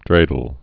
(drādl)